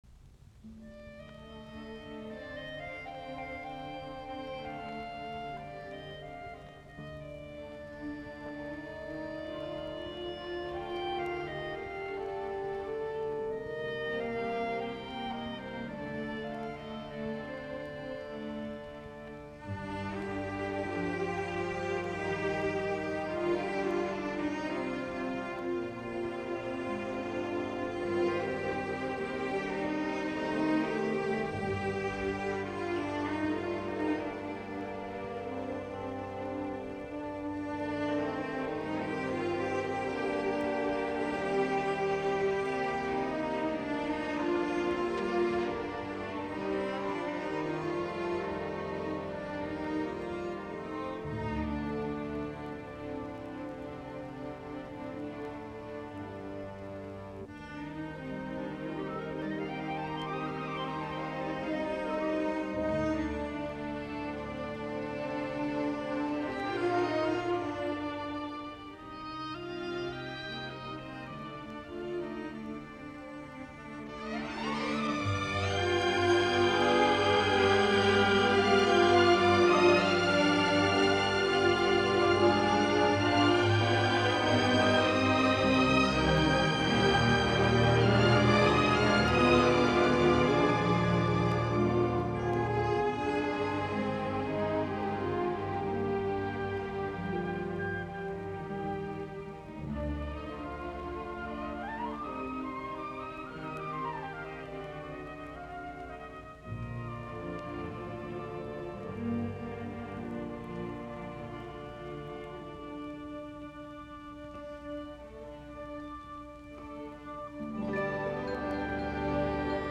musiikkiäänite
Soitinnus: ork.